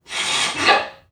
NPC_Creatures_Vocalisations_Robothead [93].wav